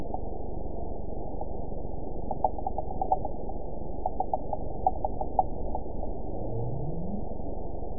event 912155 date 03/19/22 time 12:33:47 GMT (3 years, 1 month ago) score 9.05 location TSS-AB05 detected by nrw target species NRW annotations +NRW Spectrogram: Frequency (kHz) vs. Time (s) audio not available .wav